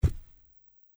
土路上的脚步声－偏低频－右声道－YS070525.mp3
通用动作/01人物/01移动状态/土路/土路上的脚步声－偏低频－右声道－YS070525.mp3